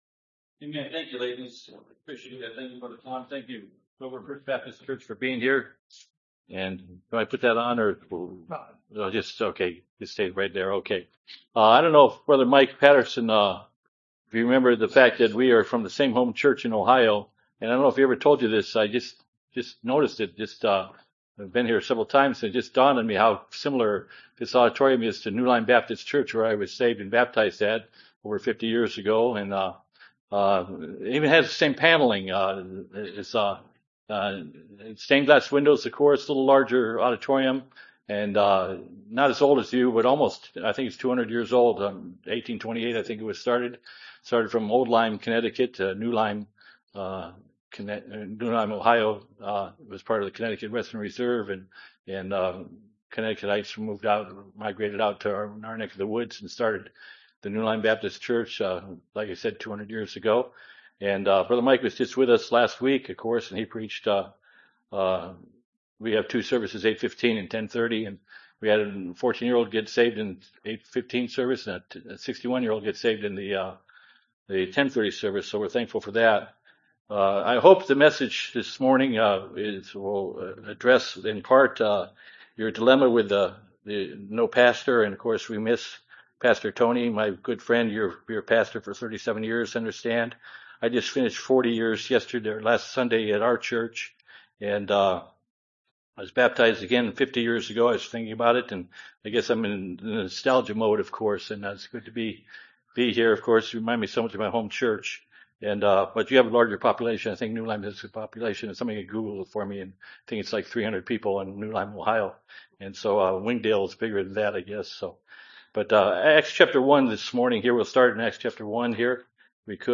Series: Exposition of Acts Passage: Acts 1:4 --Acts 2:1-47 Service Type: Sunday Morning (voice only)